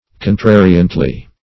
contrariantly - definition of contrariantly - synonyms, pronunciation, spelling from Free Dictionary Search Result for " contrariantly" : The Collaborative International Dictionary of English v.0.48: Contrariantly \Con*tra"ri*ant*ly\, adv.